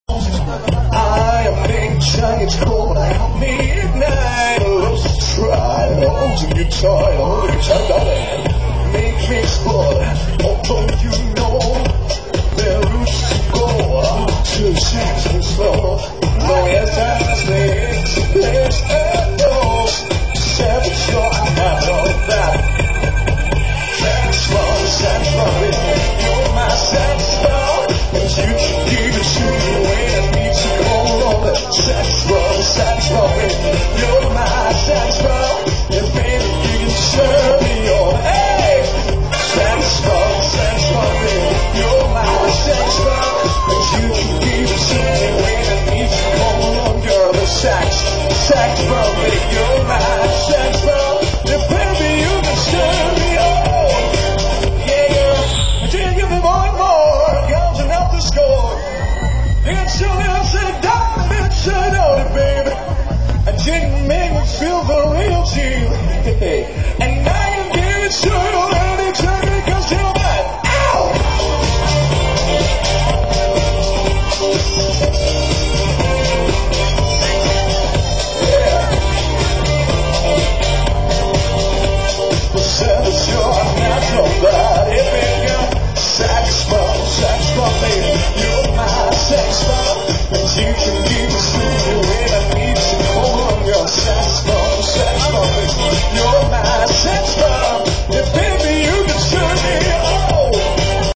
НОВОСИБИРСК, ПЛОЩАДЬ ЛЕНИНА, 4 НОЯБРЯ 2005 ГОДА